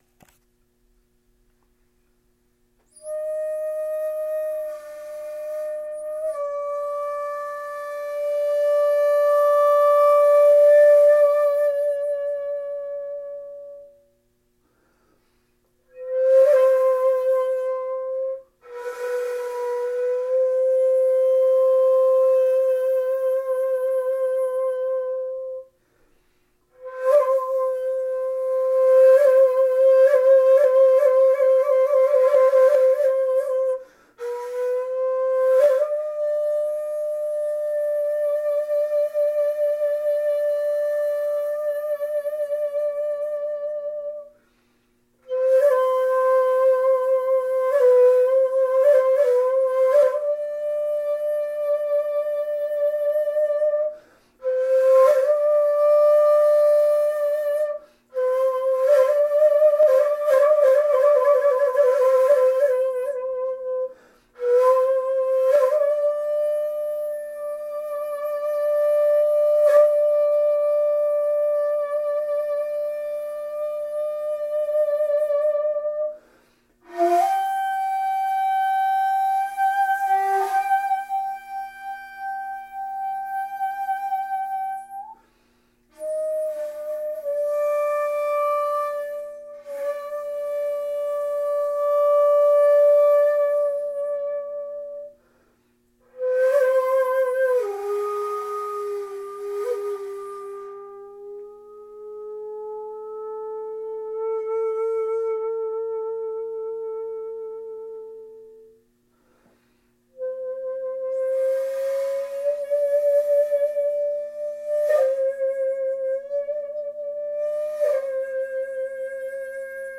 史実かどうかは別にしまして、甲音を中心とした旋律の動きは中間部の「口伝（むら息）」によってクライマックスを迎えます。
地無し延べ竹ゴロ節残し一尺八寸管